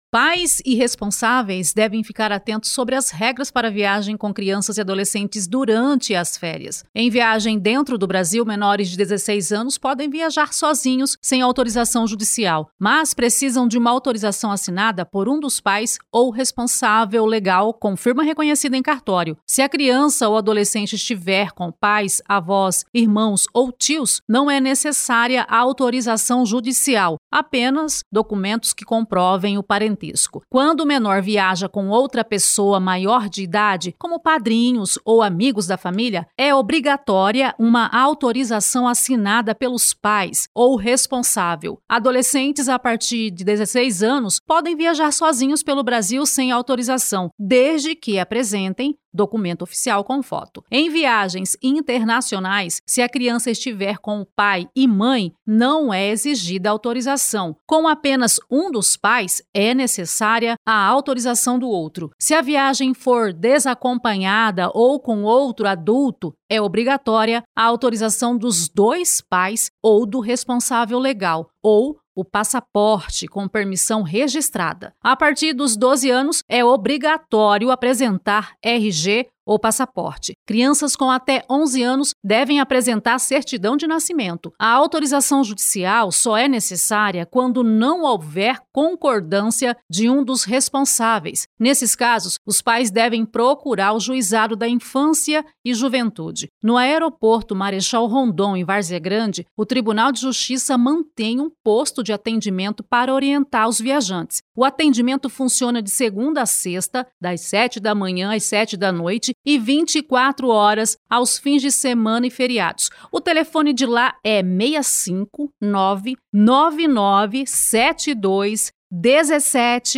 Boletins de MT 08 dez, 2025